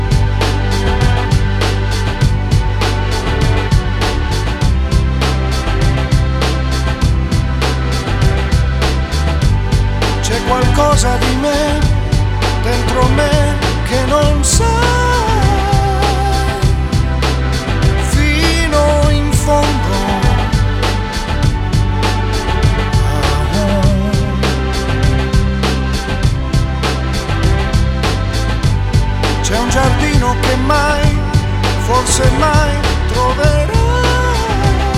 Скачать припев
1996-12-10 Жанр: Поп музыка Длительность